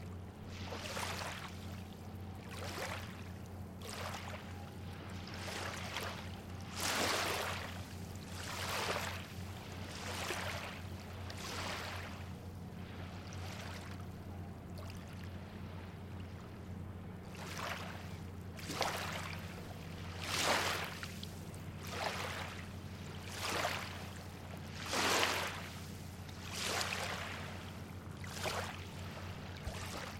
描述：Water gently rolling and lapping the shore of Lake Erie. Late Spring 2018 in the evening
标签： beach wave waves sea shore water coast seaside nature fieldrecording ambient soundscape surf
声道立体声